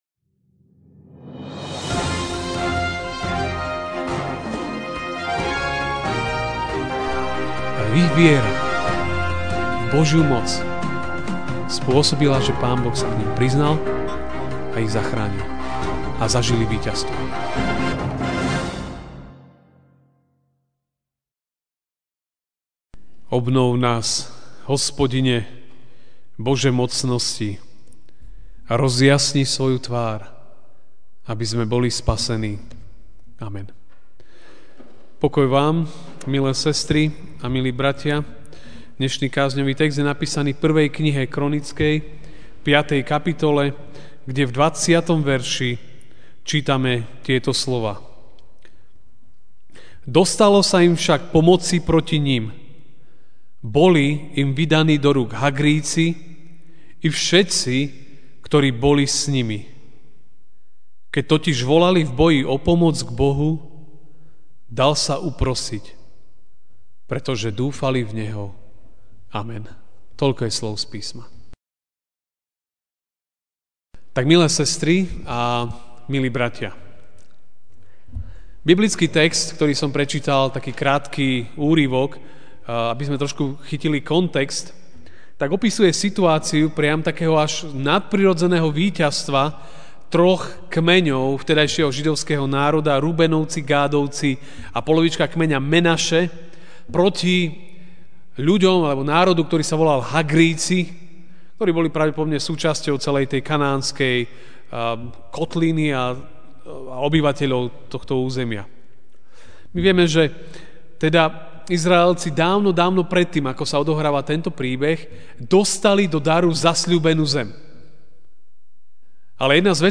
Život je zápas - Evanjelický a.v. cirkevný zbor v Žiline
Večerná kázeň: Život je zápas (1. Kron. 5,20) Dostalo sa im však pomoci proti nim, boli im vydaní do rúk Hagrijci i všetci, ktorí boli s nimi.